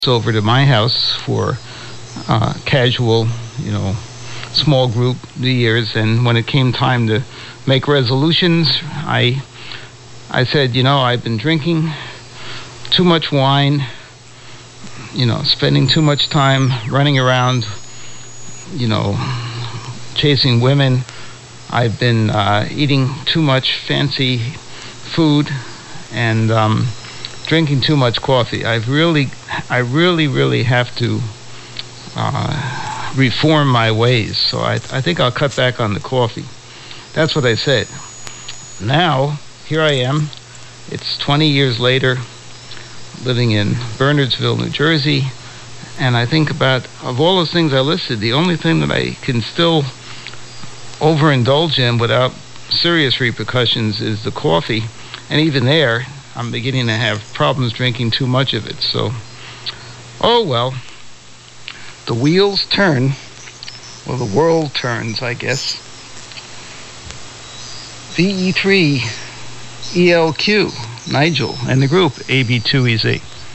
The 75 Meter AM Audio Files